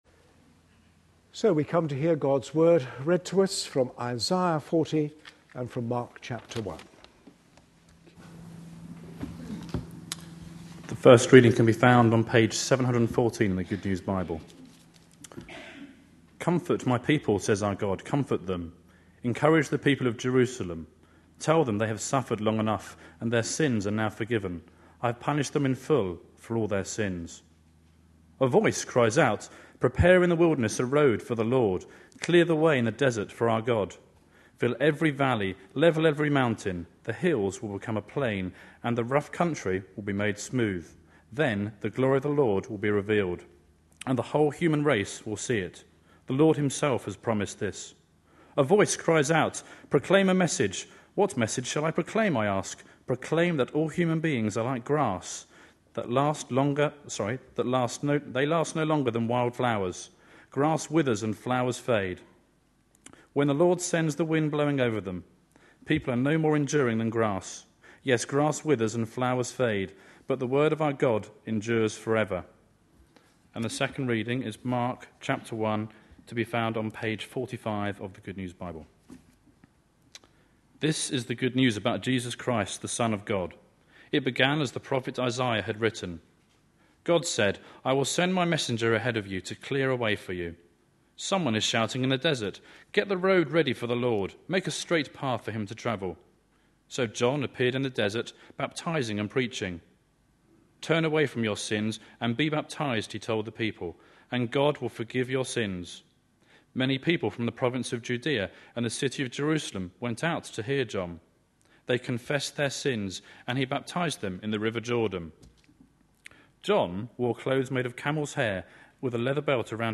A sermon preached on 4th December, 2011.